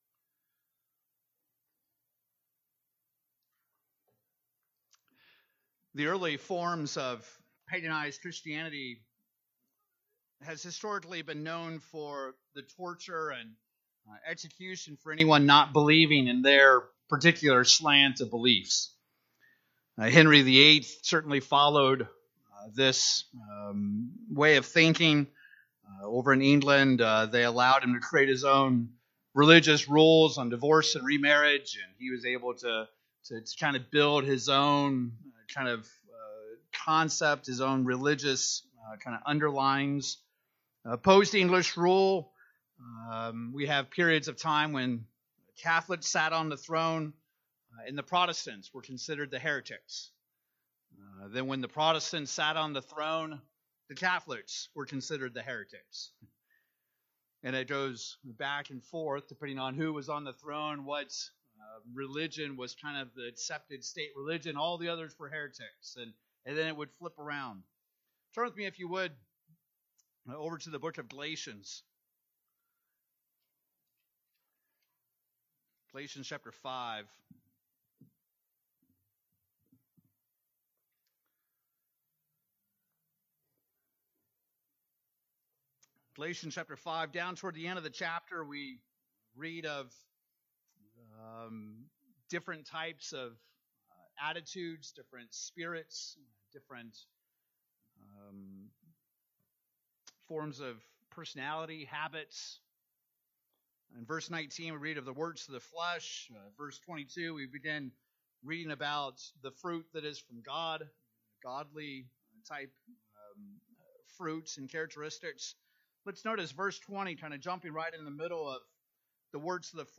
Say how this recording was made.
Sermon given in Tulsa on June 24, 2017.